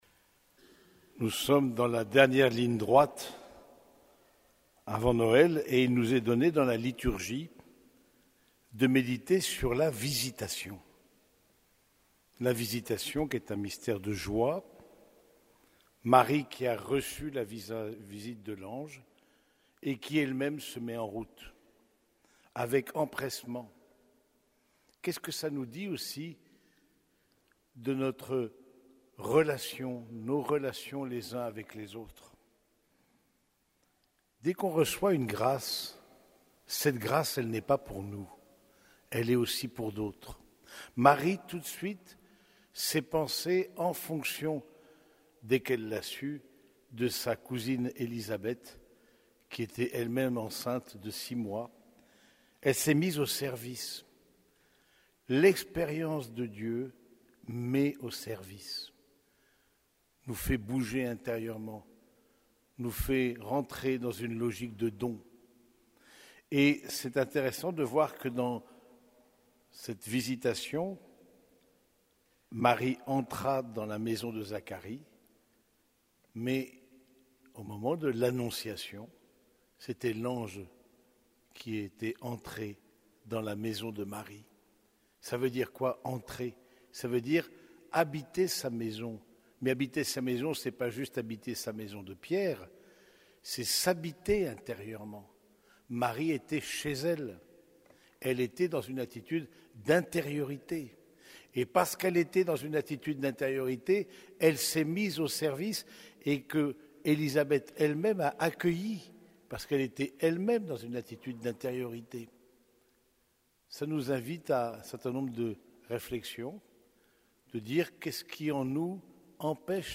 Homélie du quatrième dimanche de l'Avent